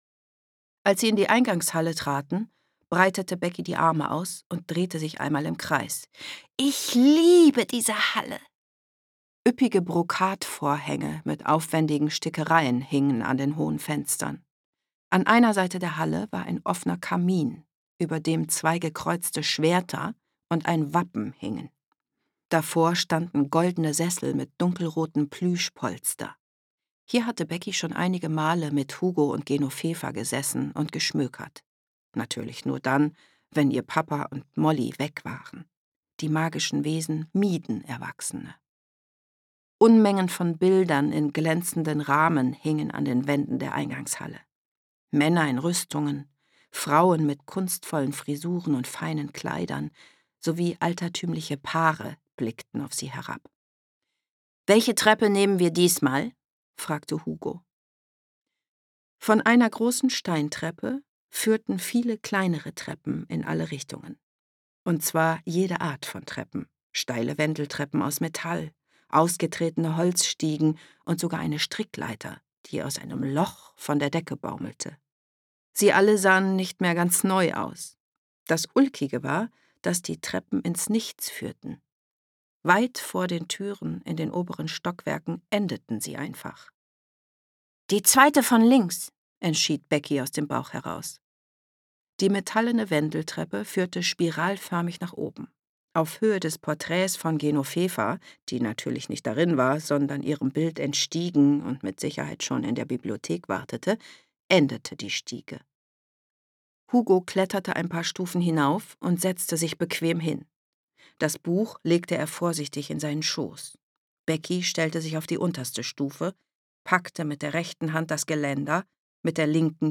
Kinderhörbuch